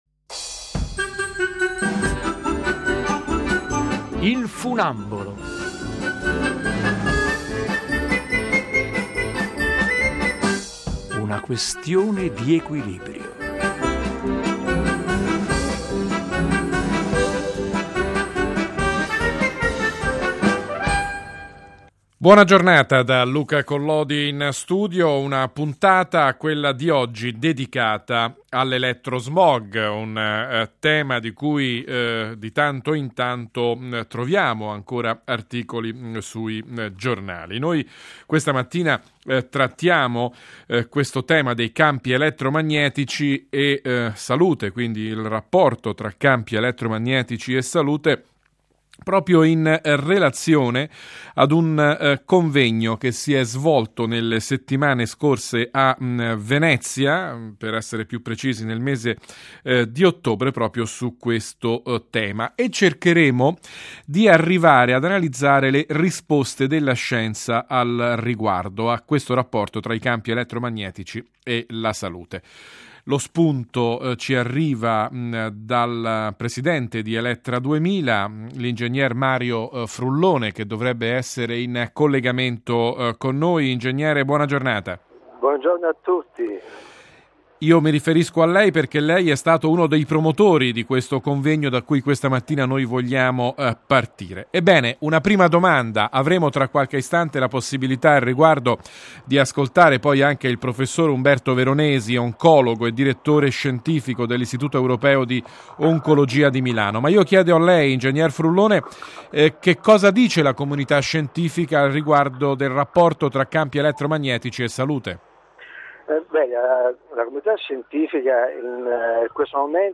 L'intervista è andata in onda sul canale in diretta della Radio Vaticana, OneOFive D. – Professor Veronesi, buona giornata!